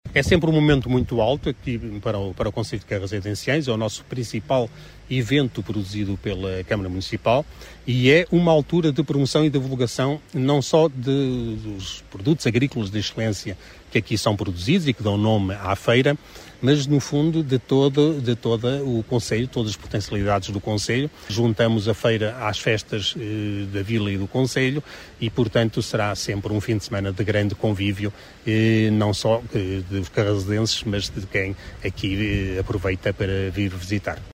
O presidente da Câmara Municipal, João Gonçalves, acredita que será um fim de semana de grande convívio e de forte divulgação dos produtos locais: